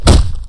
Kick4.wav